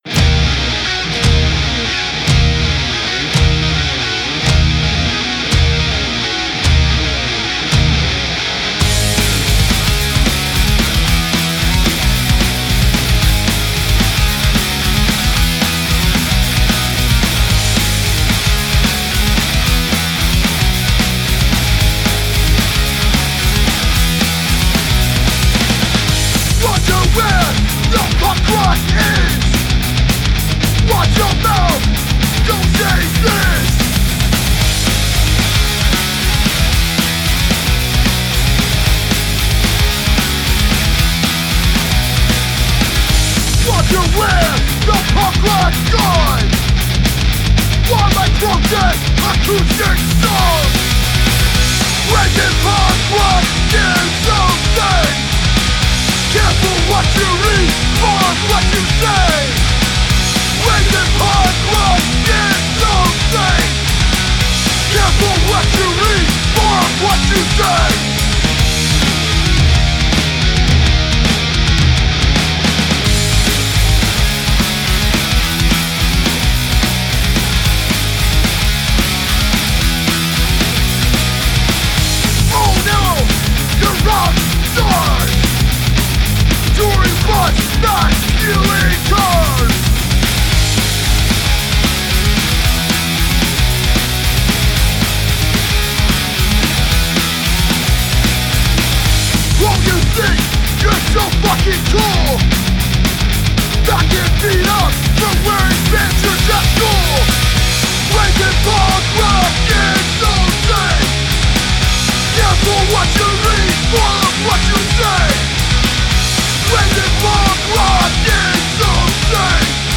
Oi! Punk